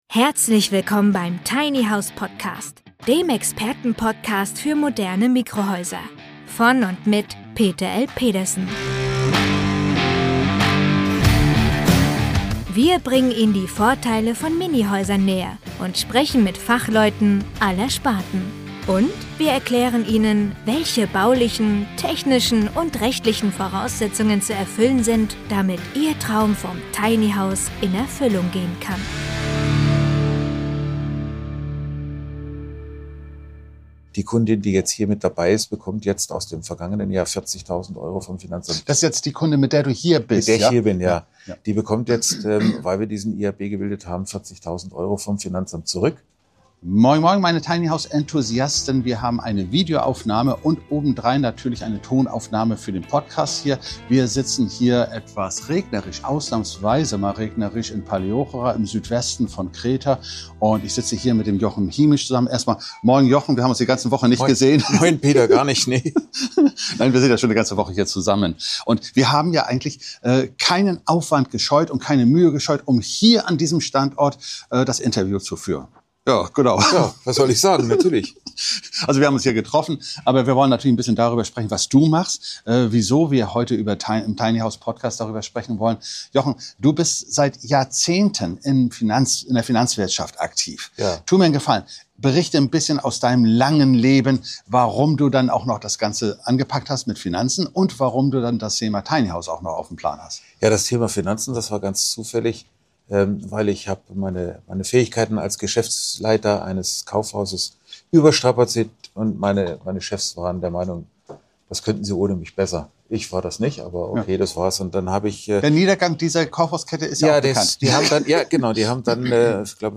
im Gespräch.